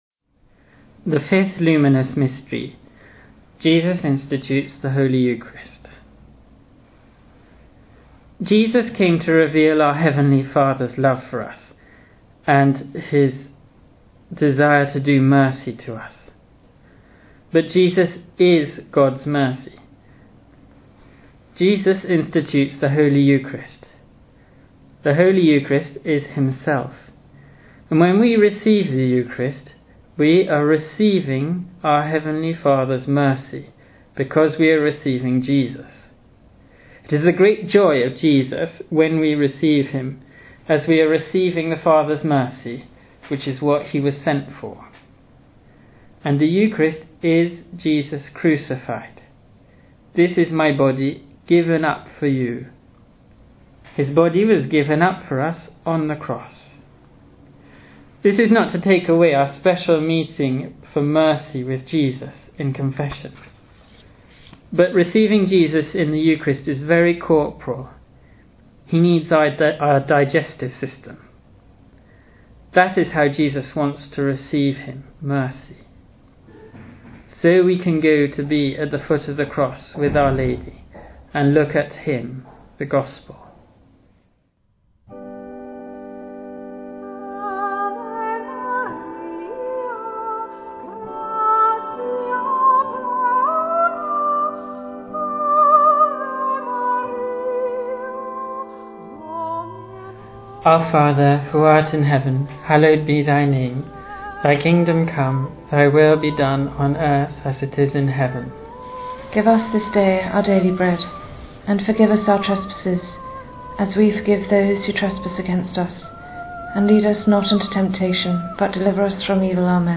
T2us-Rosary-Luminous_Mysteries-5-Institution_of_the_Eucharist.mp3